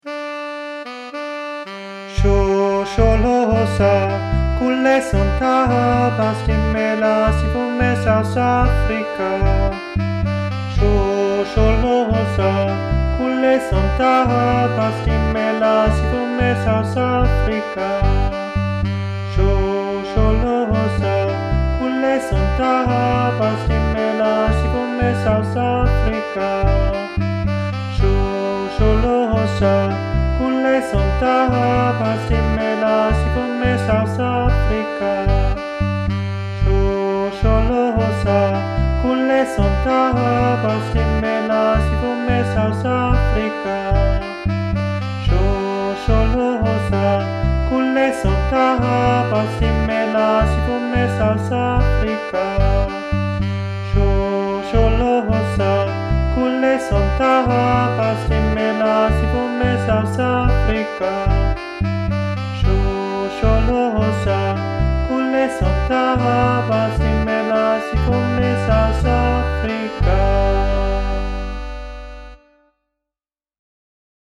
Übungsdatei 1. Stimme
3_shosholoza_sopran.mp3